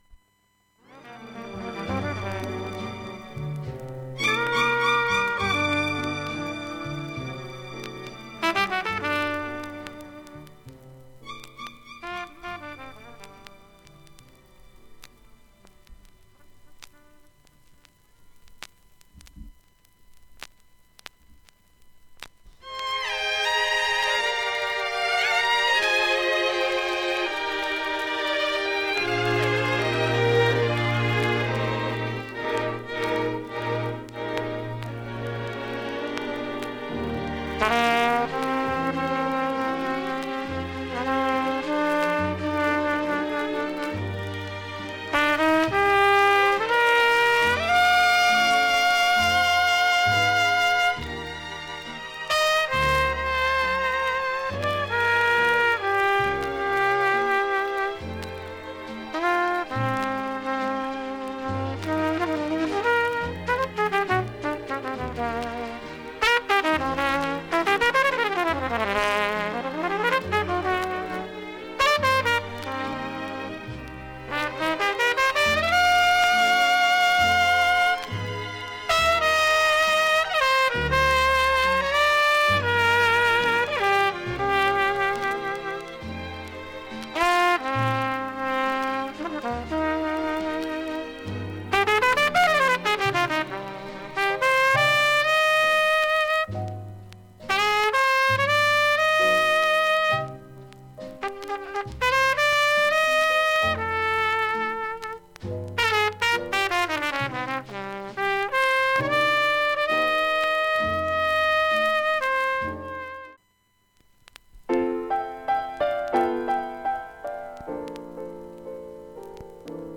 下記プツ音など小さめです。